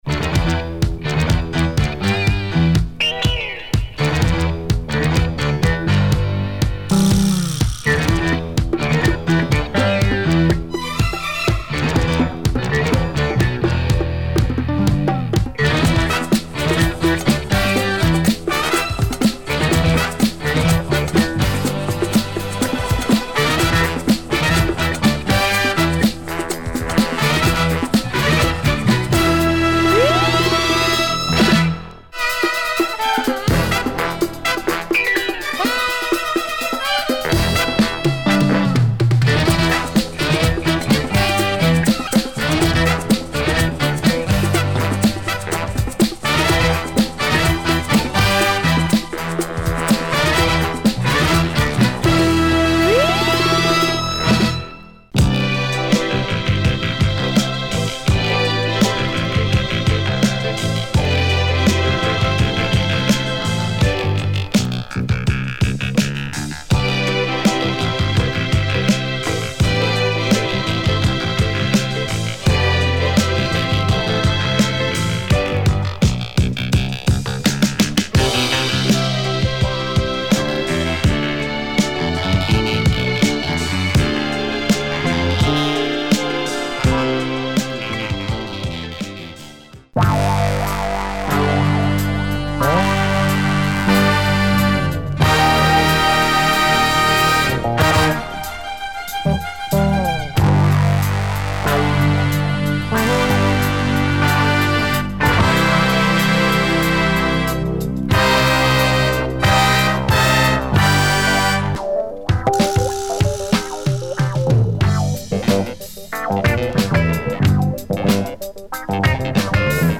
Groove and discoid funk